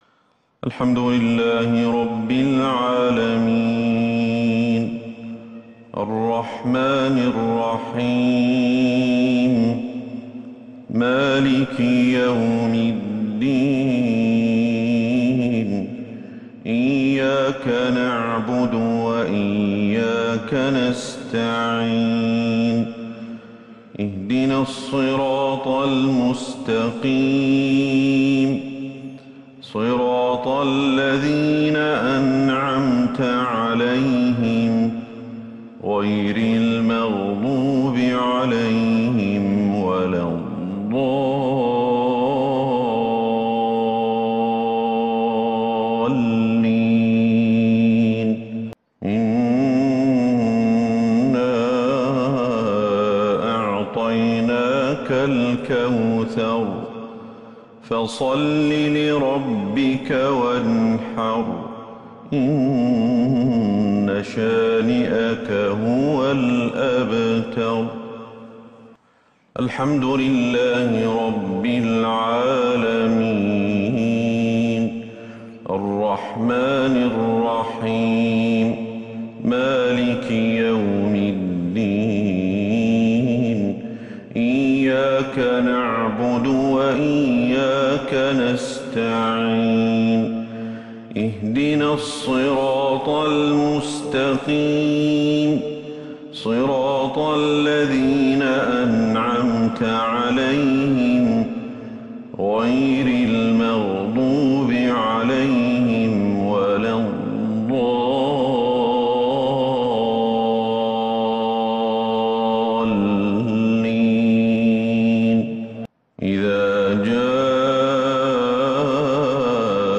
مغرب السبت 4 صفر 1443 هـ سورتي {الكوثر}{النصر} > 1443 هـ > الفروض - تلاوات الشيخ أحمد الحذيفي